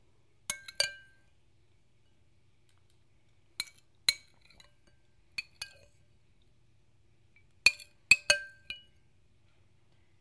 spoon clanking in ceramic bowl
bowl breakfast ceramic cereal ding dish eating kitchen sound effect free sound royalty free Sound Effects